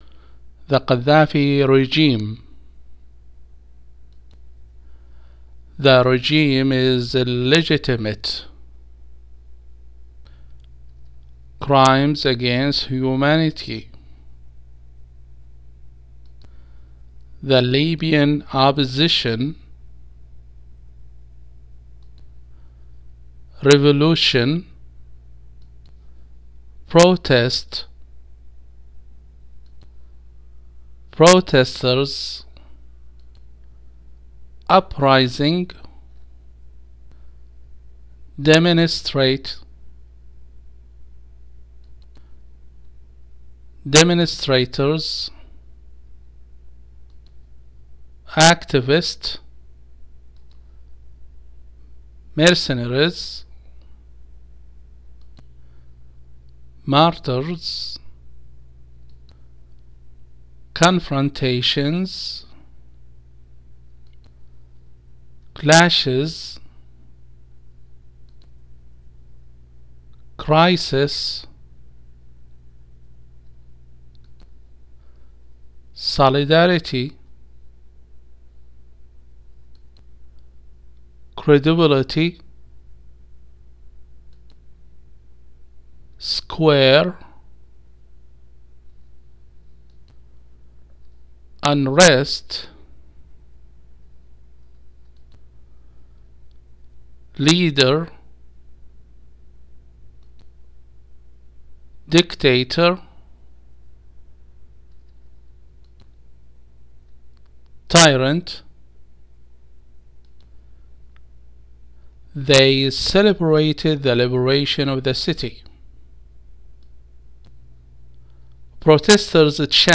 لتحميل المقطع الصوتي لنطق النص، اضغط بالزر الايمن على الصورة